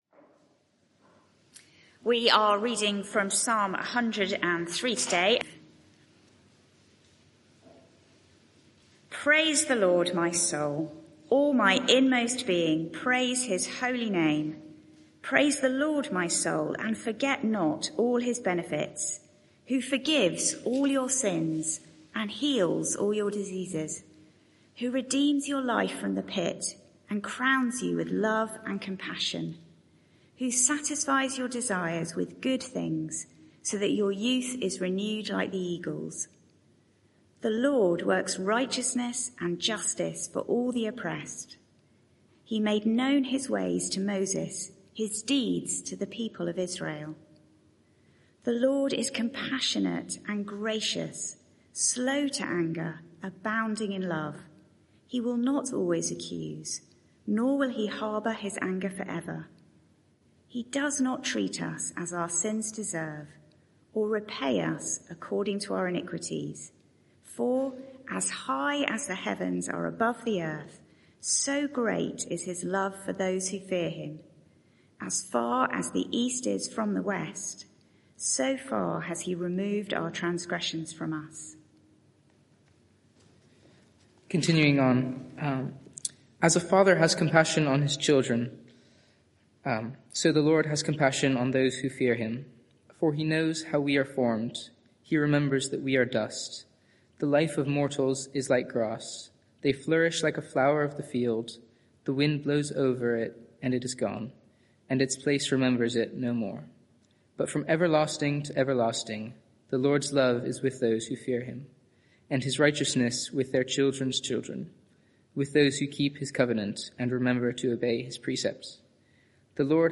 Media for 6:30pm Service on Sun 18th May 2025 18:30 Speaker
Sermon (audio) Search the media library There are recordings here going back several years.